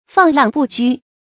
放浪不拘 注音： ㄈㄤˋ ㄌㄤˋ ㄅㄨˋ ㄐㄨ 讀音讀法： 意思解釋： 見「放浪不羈」。